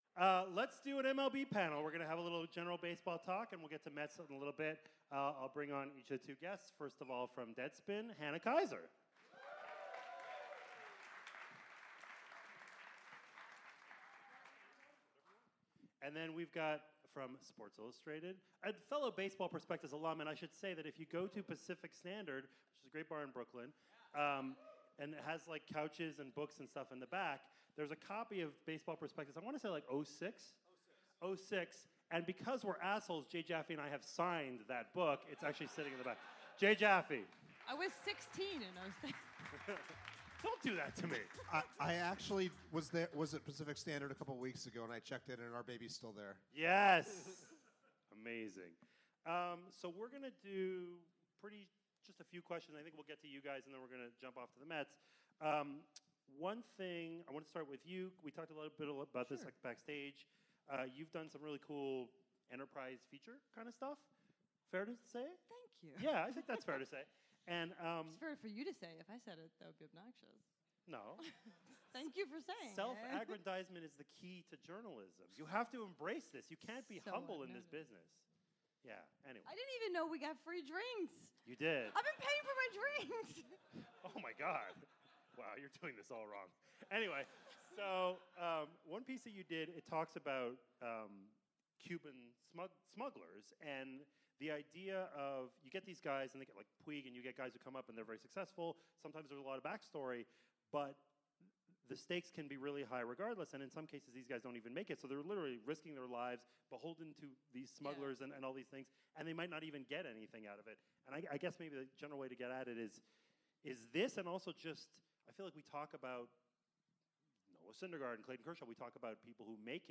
Pitch Talks comes to you live from The Bell House in Brooklyn